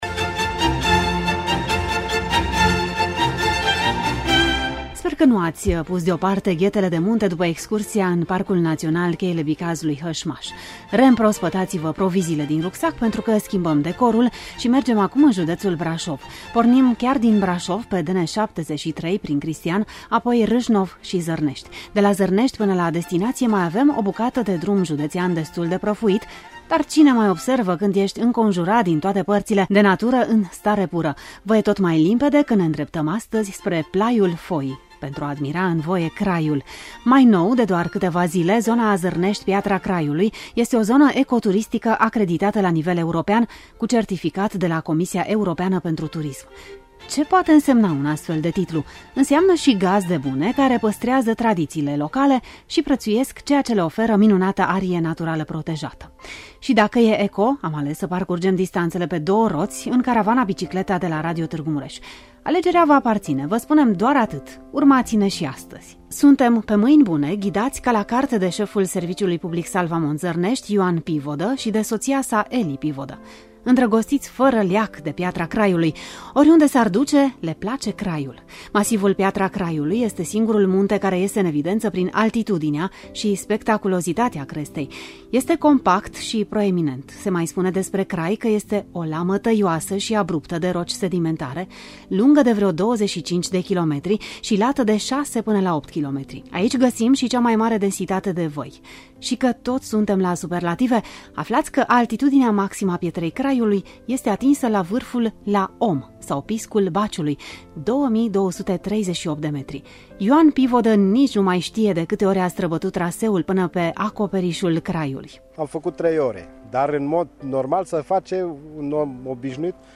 Ne face plăcere să ne însoţiţi pentru un traseu mai lung, accesibil in reportajul de mai jos: